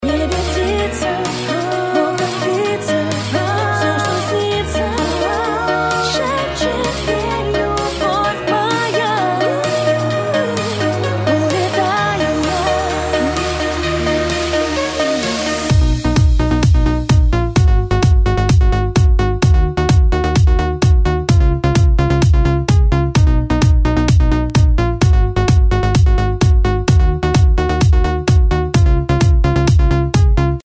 Главная » Файлы » Клубные рингтоны